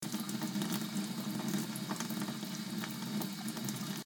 Sink free sound effects